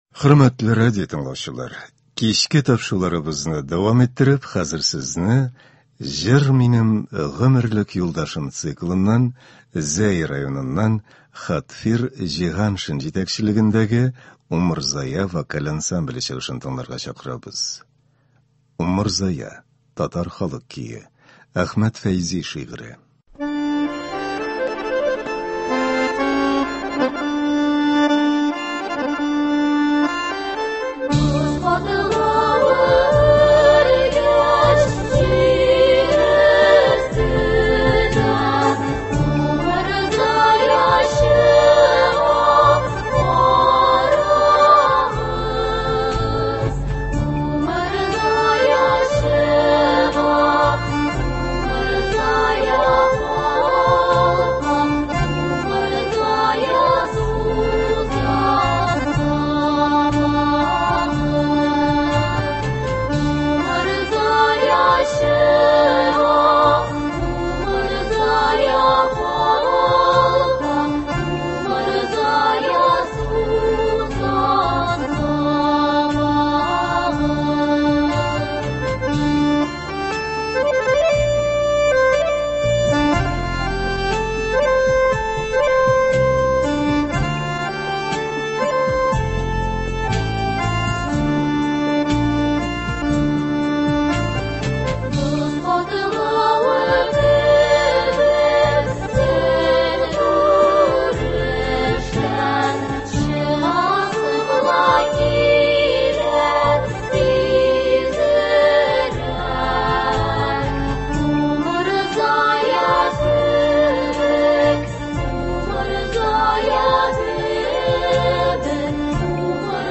Үзешчән башкаручылар чыгышы.
Концерт (19.02.24)